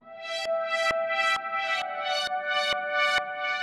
Index of /musicradar/uk-garage-samples/132bpm Lines n Loops/Synths